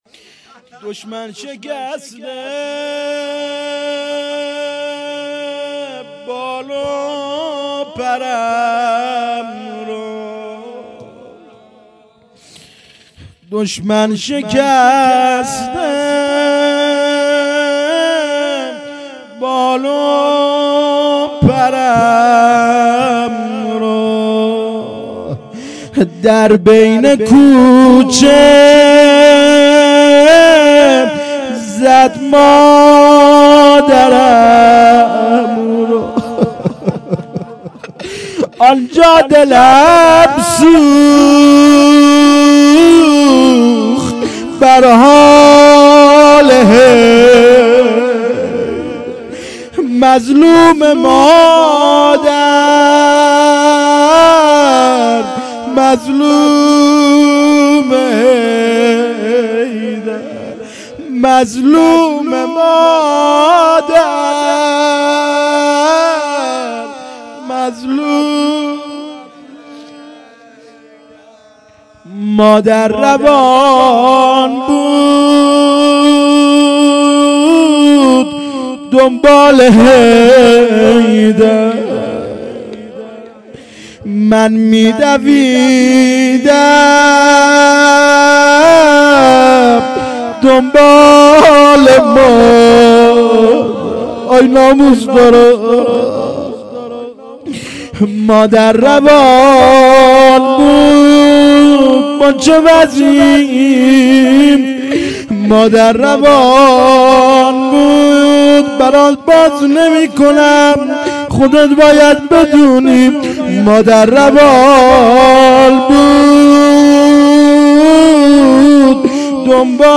روضه
مراسم هفتگی ۶ دیماه